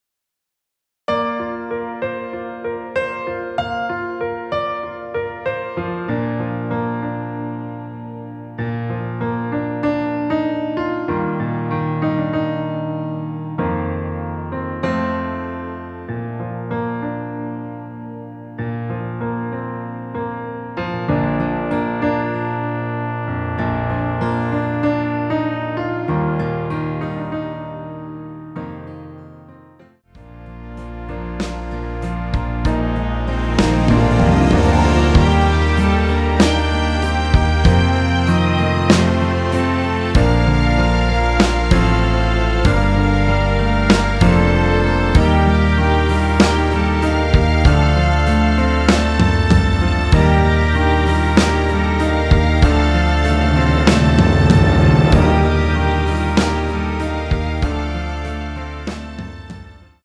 Bb
사운드 깔끔하고 좋아요
앞부분30초, 뒷부분30초씩 편집해서 올려 드리고 있습니다.